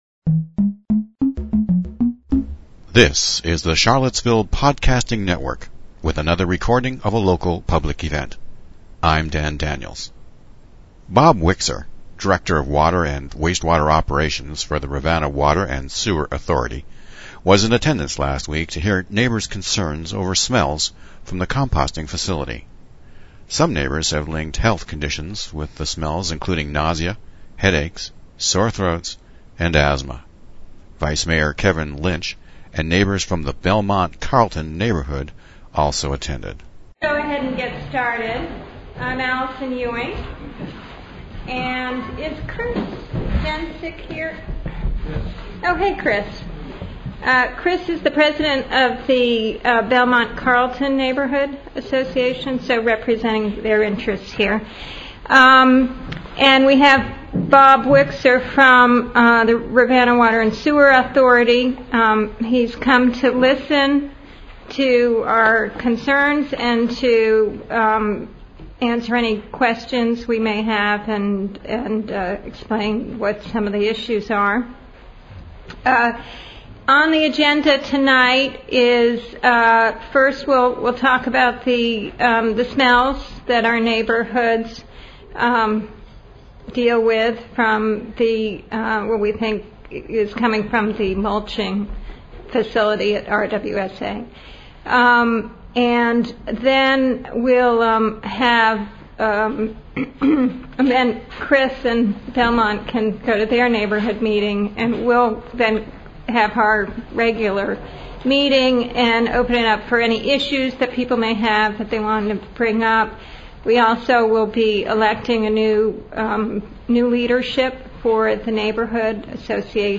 Smelly Town: Woolen Mills residents speak out at recent Rivanna Water and Sewer Authority meeting